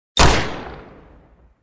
دانلود صدای هفت تیر از ساعد نیوز با لینک مستقیم و کیفیت بالا
جلوه های صوتی
برچسب: دانلود آهنگ های افکت صوتی اشیاء دانلود آلبوم صدای کلت از افکت صوتی اشیاء